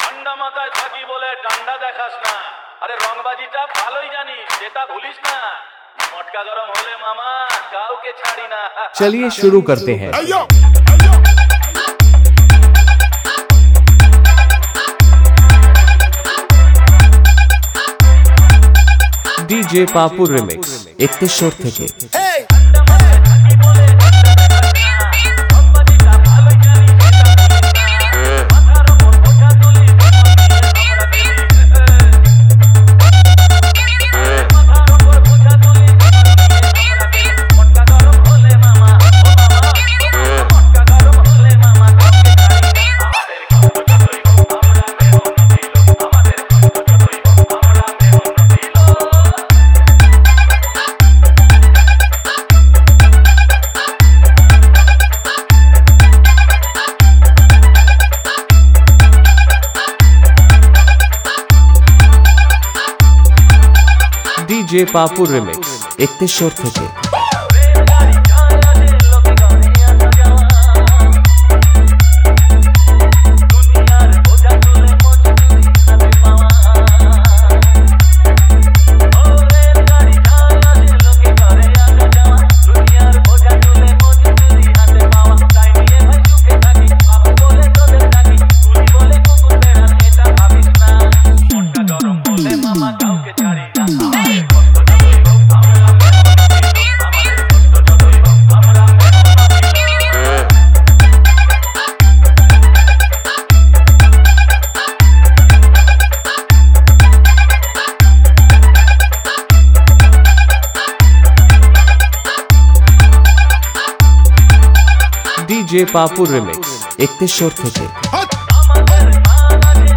1 Step Long Humbing Horror Music Mix